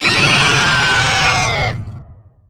Dragon Death Roar Sound
horror
Dragon Death Roar